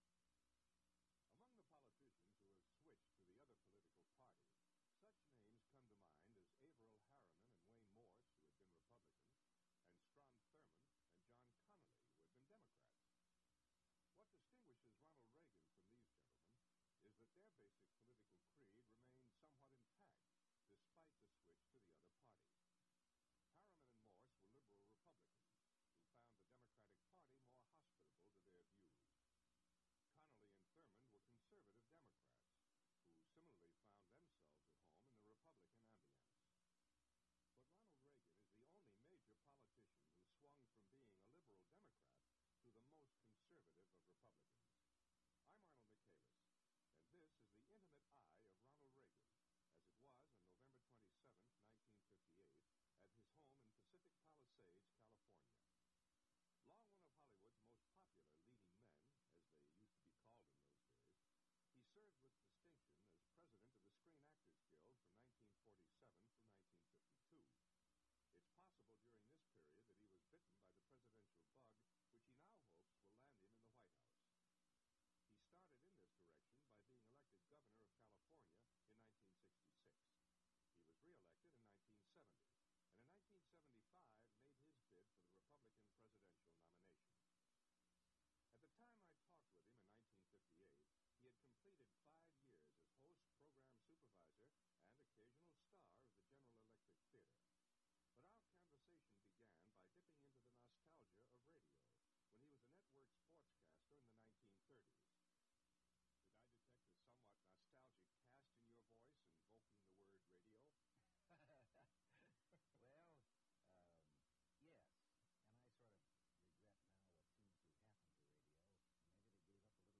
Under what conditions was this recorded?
Audio Cassette Format. MP3 Audio file (Subjects: Radio, Labor, SAG, Adoption)